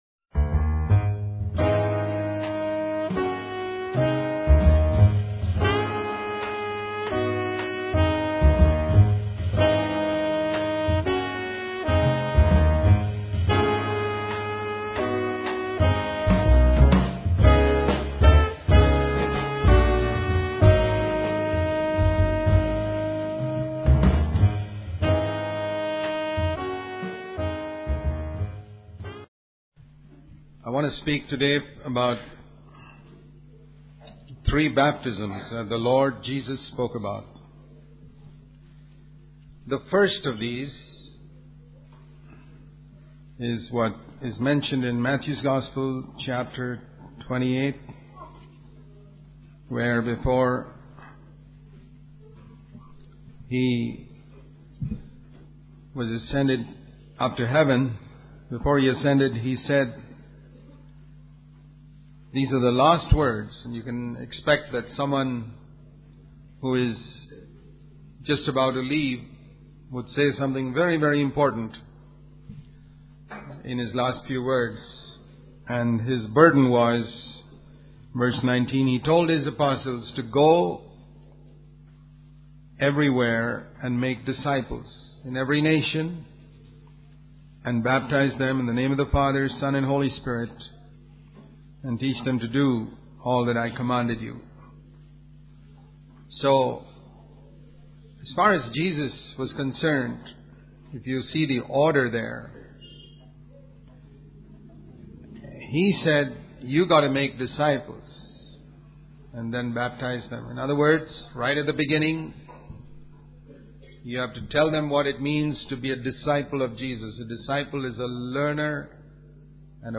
In this sermon, the speaker emphasizes the unique foundations of Christianity: that Christ died for the sins of the world and rose from the dead.